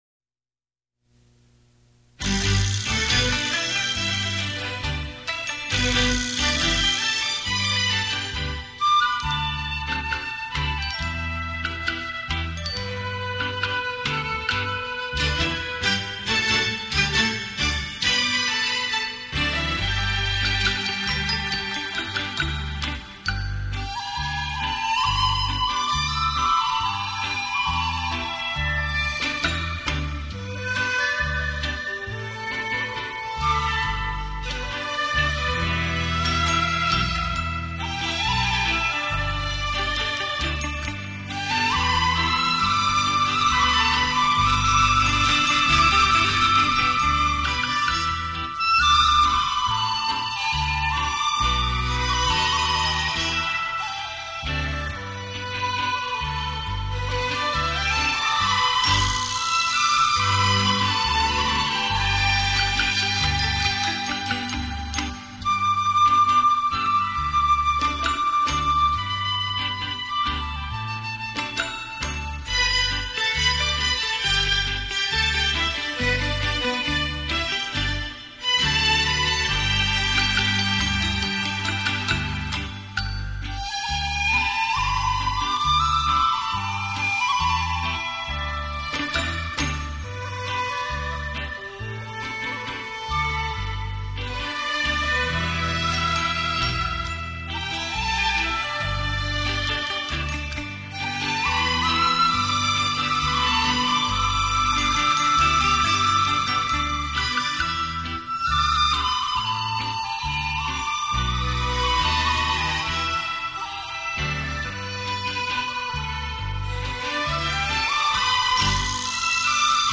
用洞箫悠扬的音色带您回味这曲曲脍炙人口扣人心弦的经典好歌。